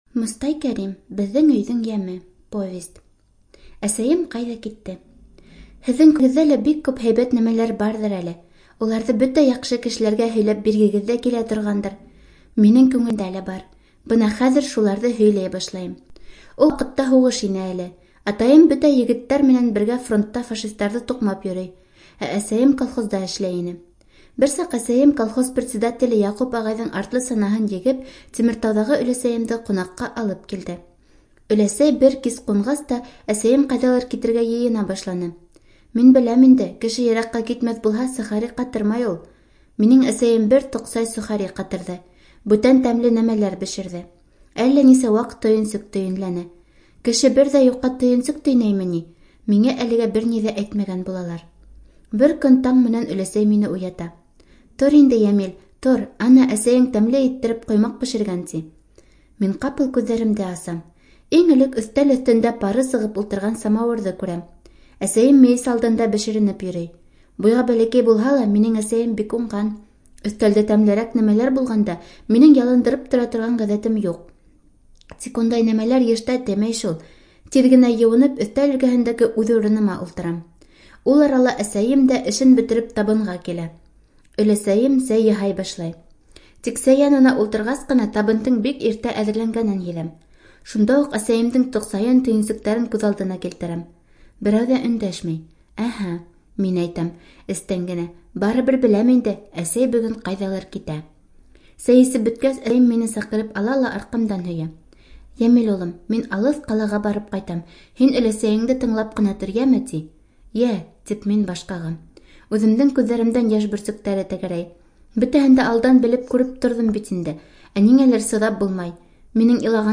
Студия звукозаписиБашкирская республиканская специальная библиотека для слепых